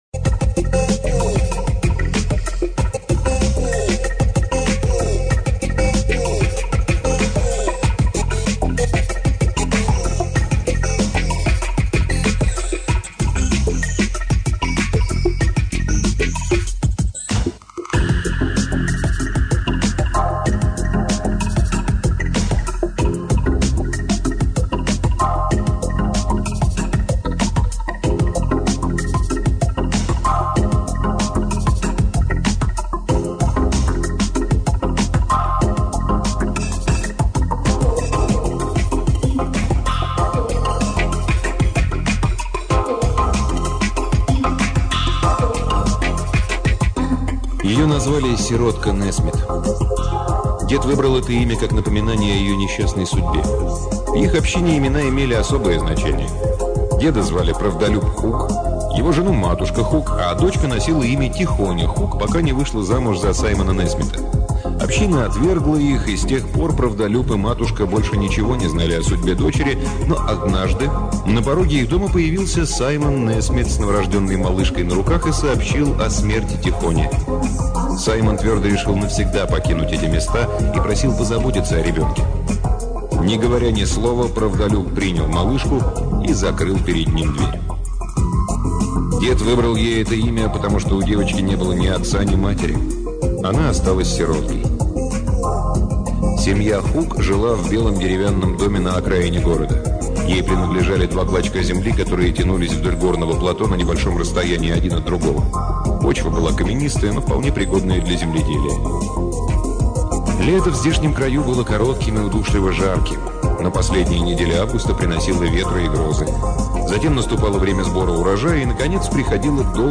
Аудиокнига Питер Дикинсон — Песня детей моря